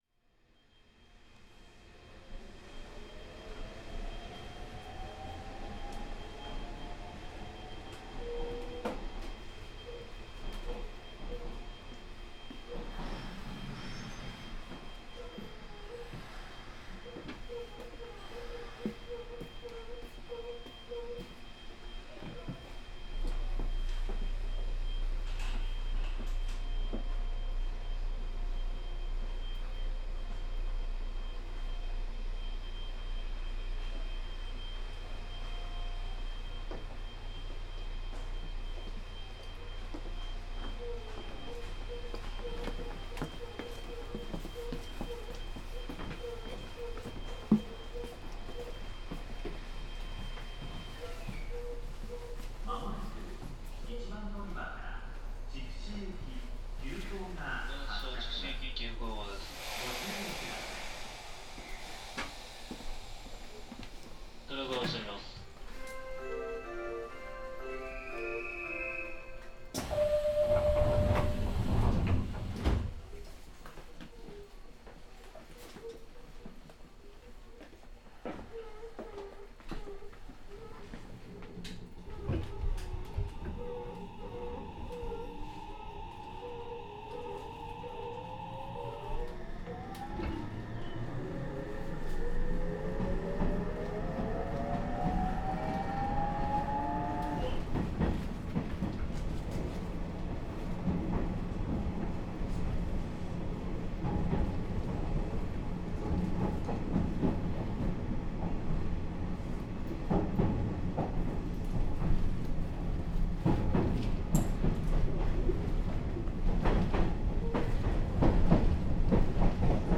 西日本鉄道 313形 ・ 走行音(機器更新車) (1.09MB) 収録区間：貝塚線 和白→唐の原 制御方式：抵抗制御(カルダン駆動) 主電動機：HS-836-Frb(120kW) 1952(昭和27)年に登場した車両。